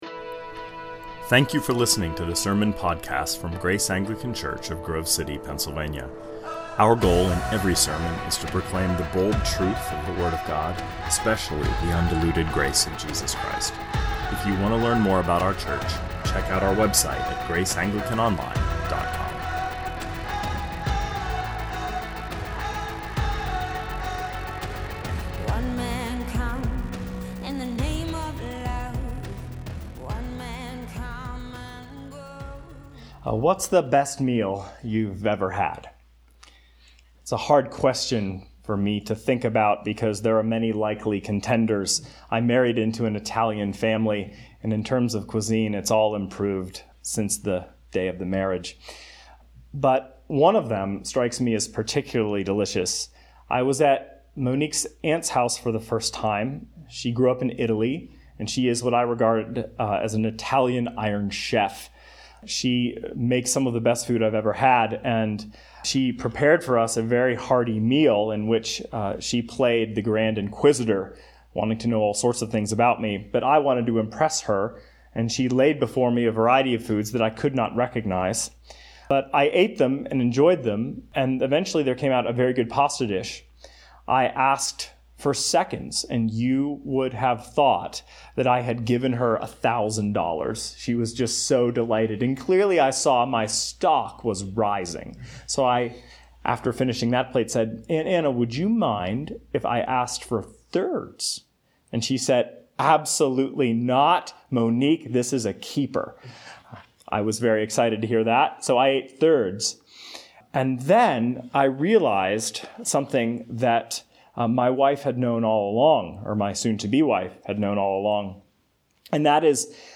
2020 Sermons Eat the Pain - A Maundy Thursday Reflection Play Episode Pause Episode Mute/Unmute Episode Rewind 10 Seconds 1x Fast Forward 30 seconds 00:00 / 20:32 Subscribe Share RSS Feed Share Link Embed